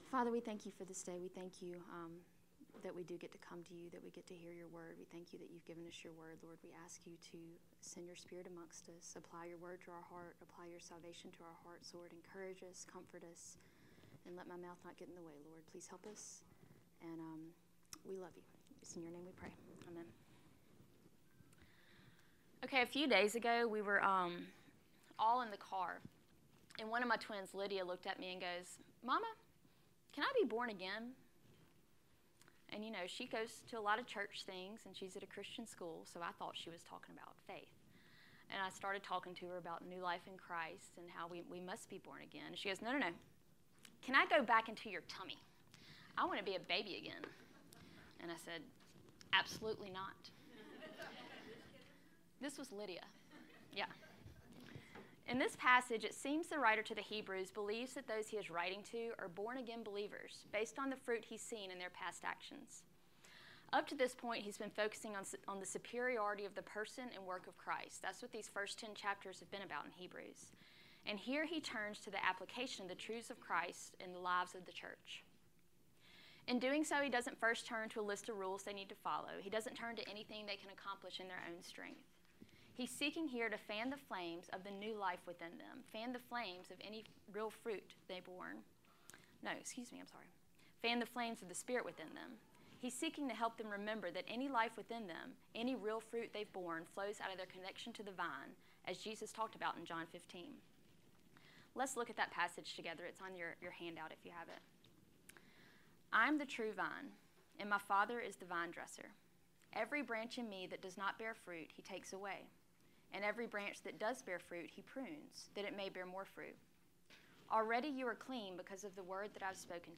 Hebrews Lesson 14